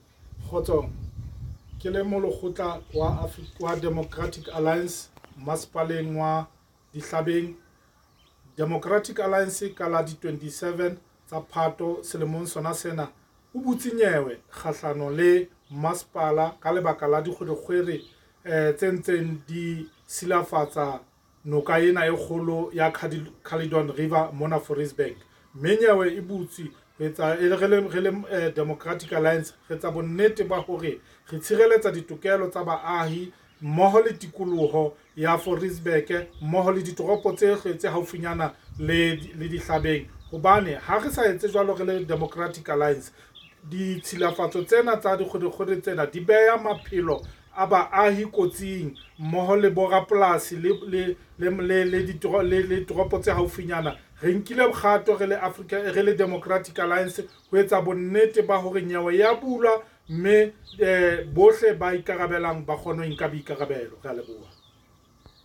Sesotho by Cllr Sello Mokoena.
Sotho-voice-Sello.mp3